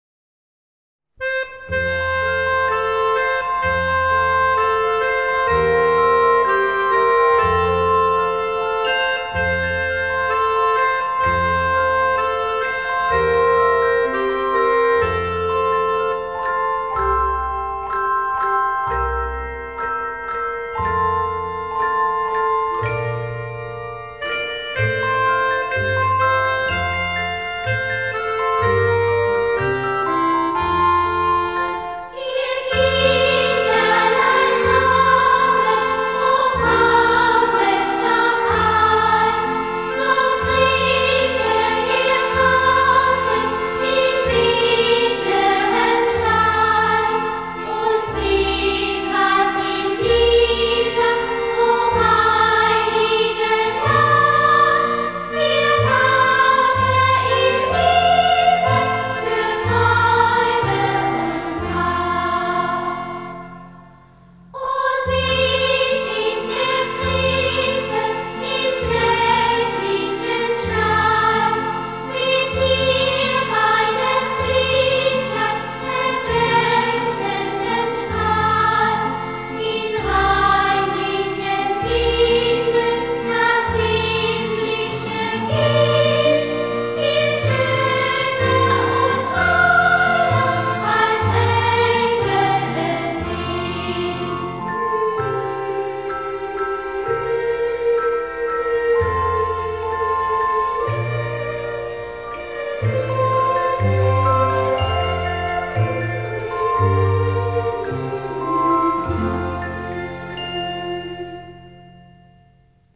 Atenção : Leia o texto a seguir ouvindo a música de natal... Regule o som colocando na altura de sua preferencia.